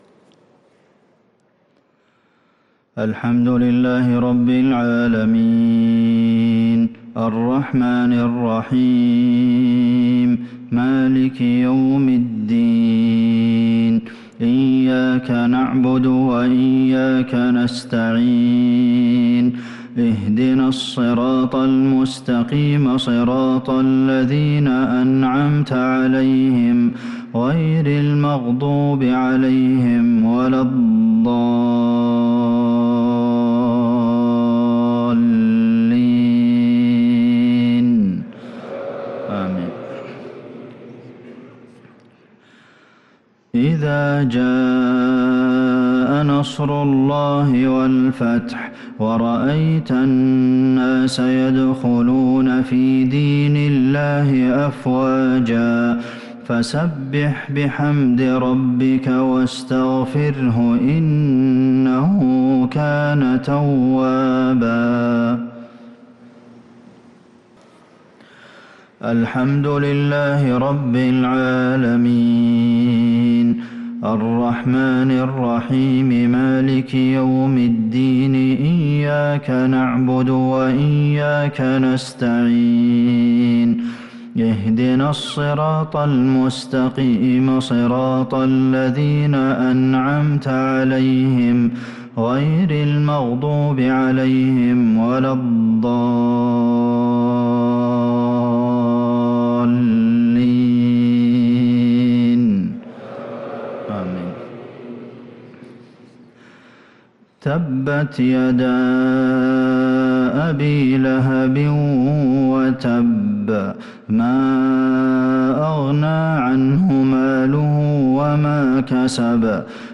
صلاة المغرب للقارئ عبدالمحسن القاسم 4 ذو الحجة 1443 هـ
تِلَاوَات الْحَرَمَيْن .